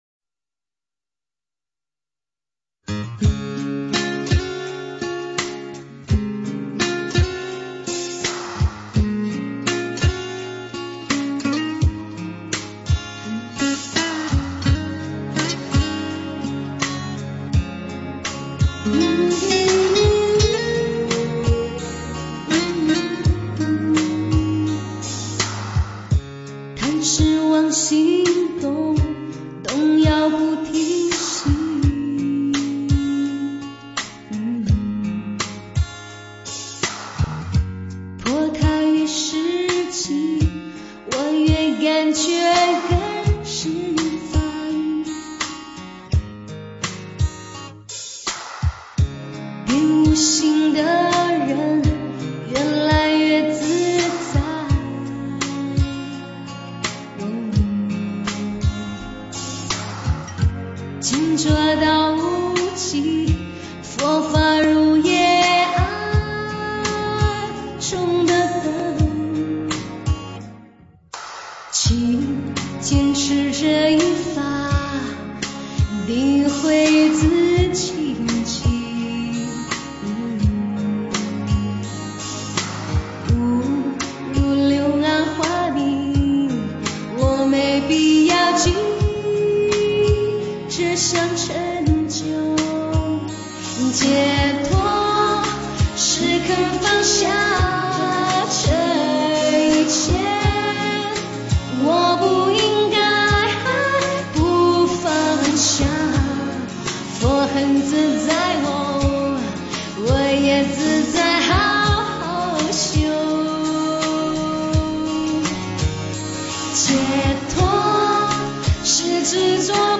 解脱 - 诵经 - 云佛论坛